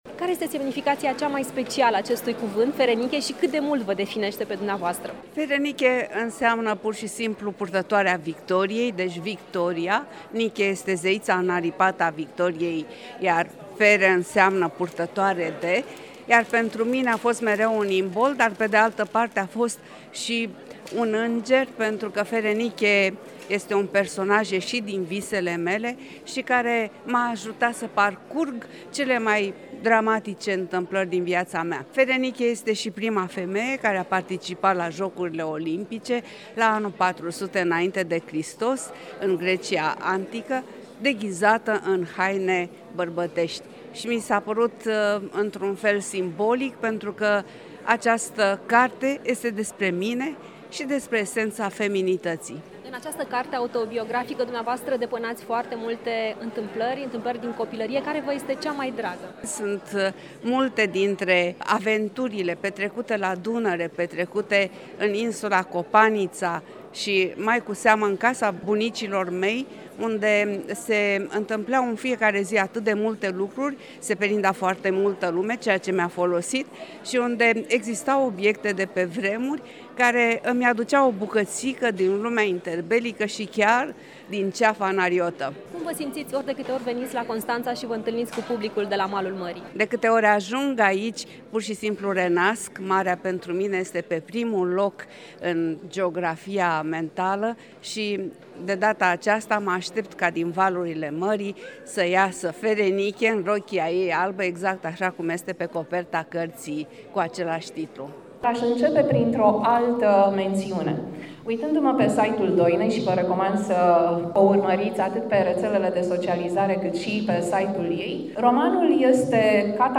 Doina Ruști a subliniat, la întâlnirea cu publicul constănțean, că orice eveniment, aparent nesemnificativ, din perioada copilăriei noastre poate avea consecințe.